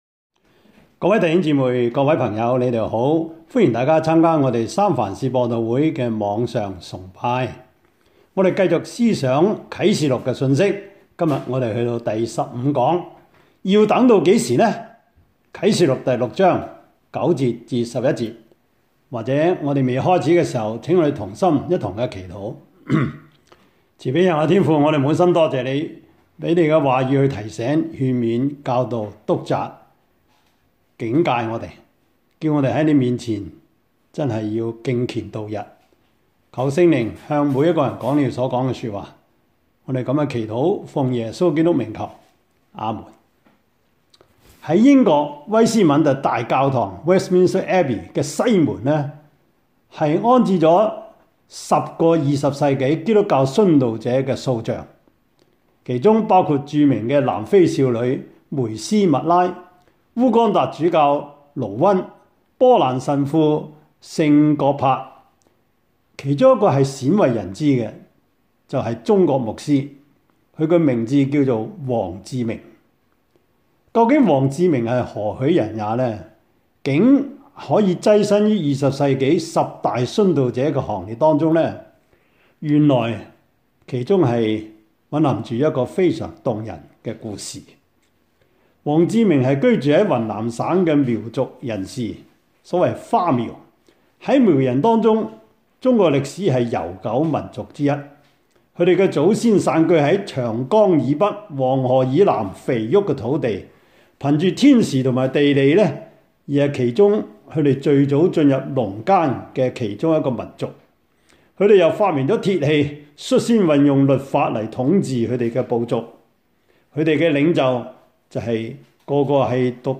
Service Type: 主日崇拜
Topics: 主日證道 « 神的國近了 新酒裝在新皮袋裏 »